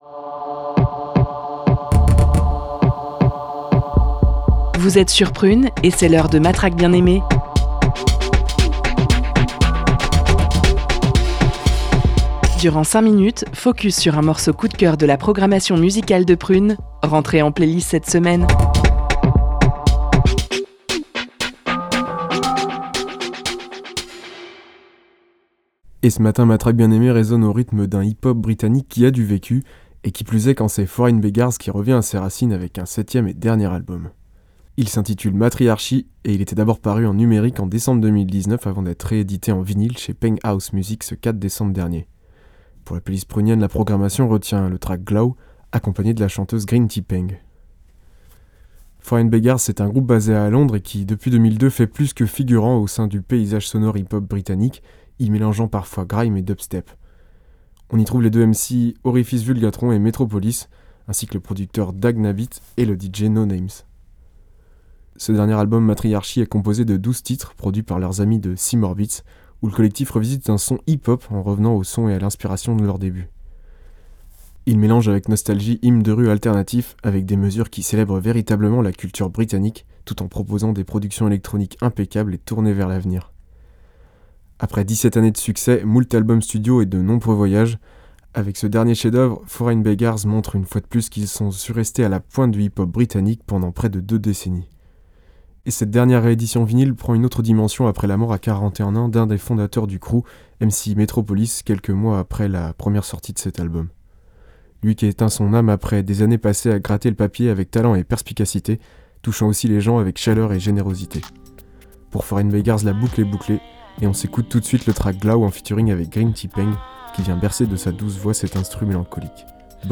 hip hop britannique